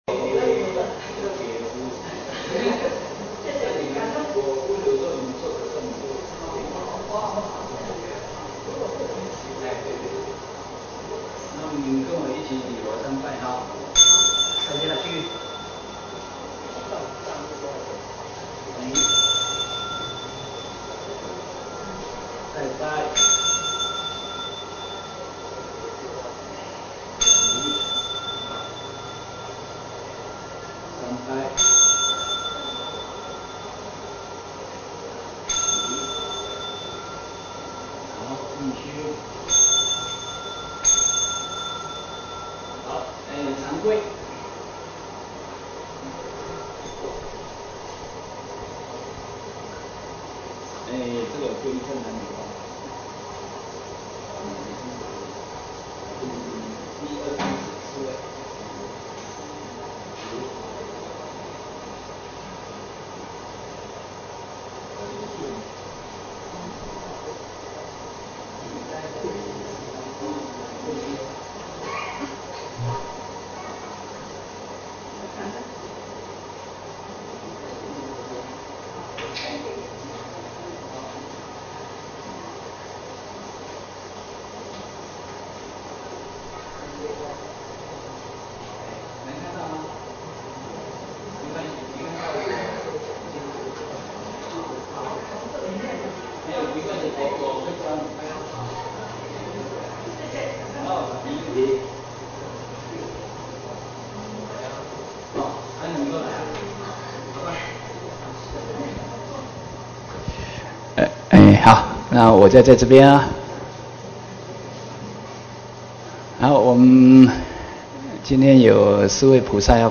三皈依开示05.mp3